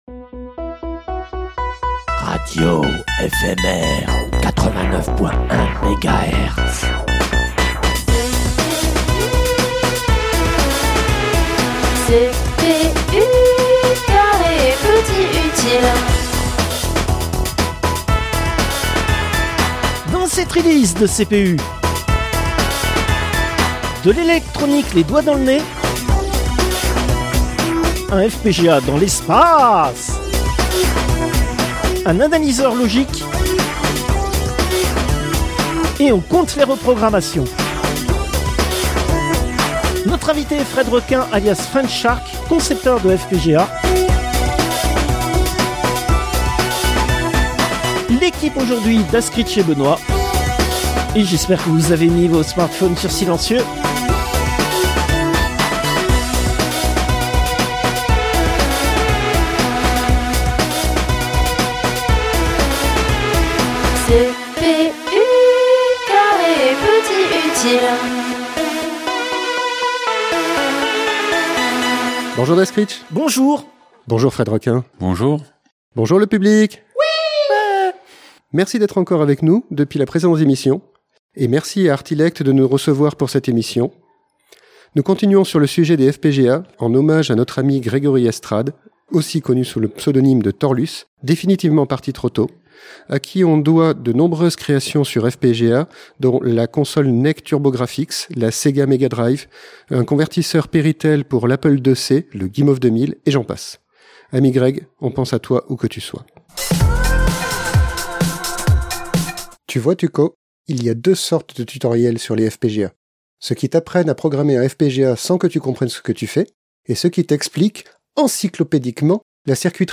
L'interview a été enregistrée en Octobre 2025 au sein du Fablab Artilect avec l'aide de l'association Silicium pour leur soutien logistique.